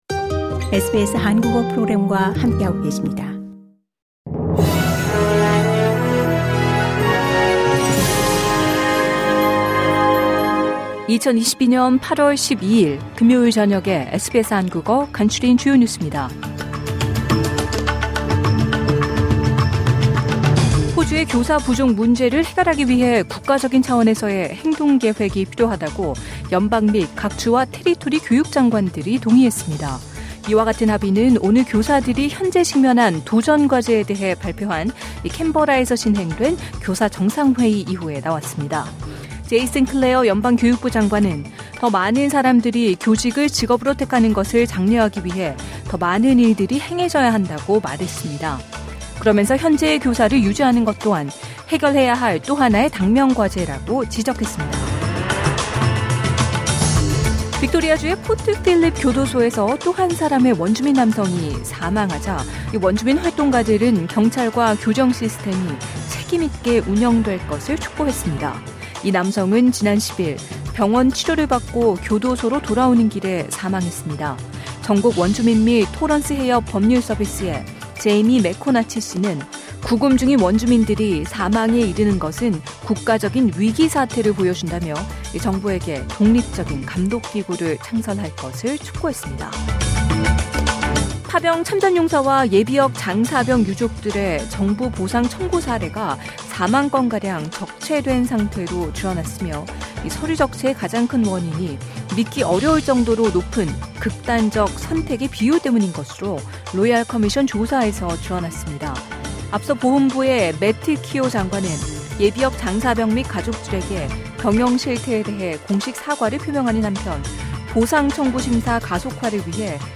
2022년 8월 12일 금요일 저녁 SBS 한국어 간추린 주요 뉴스입니다.